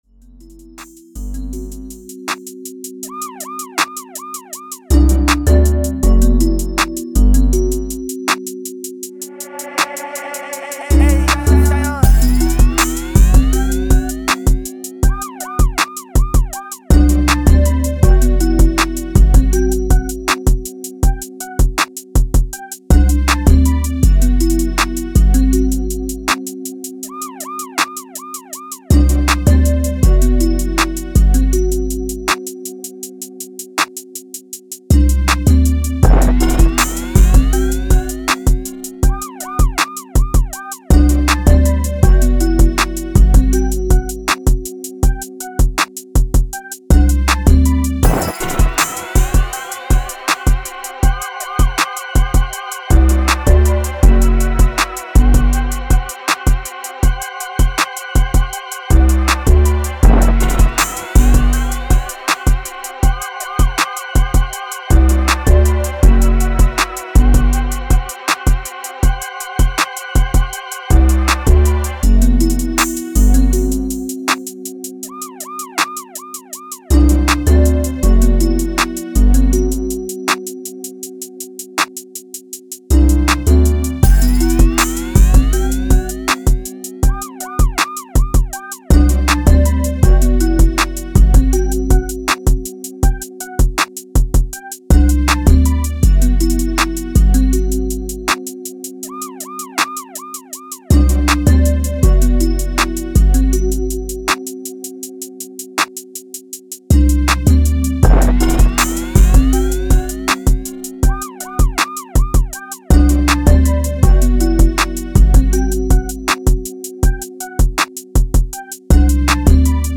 160 E Minor